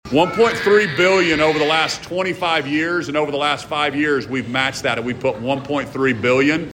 Governor Kevin Stitt signed what he called a historic school funding bill during a State Capitol ceremony Thursday afternoon.
“One 1.3 billion over the last 25 years, and over the last five years, we have matched that, and we have put 1.3 billion…,” Stitt said.